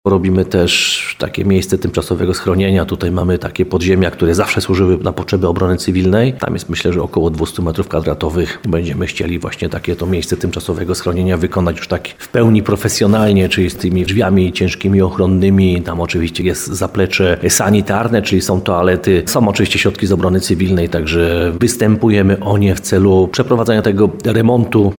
Teraz mają jednak ruszyć też kolejne prace pod budynkiem urzędu – mówi burmistrz Piotr Ryba.